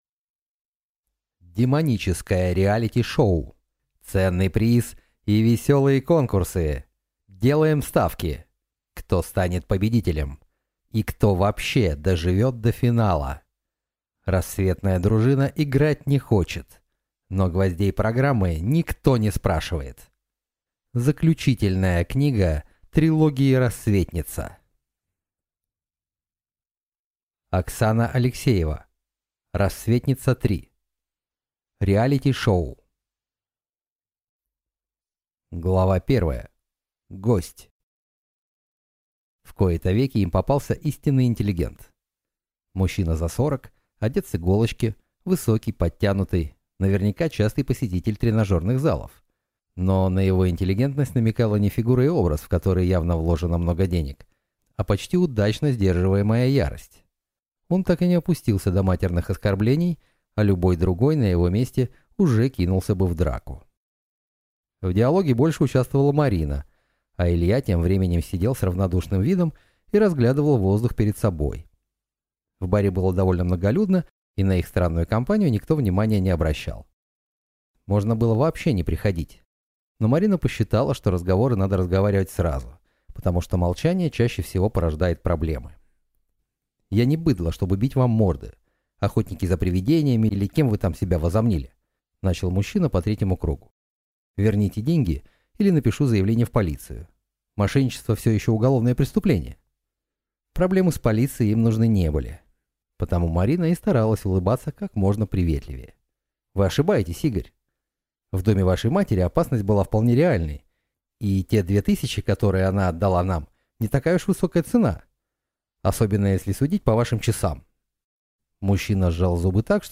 Aудиокнига Рассветница-3: Реалити-шоу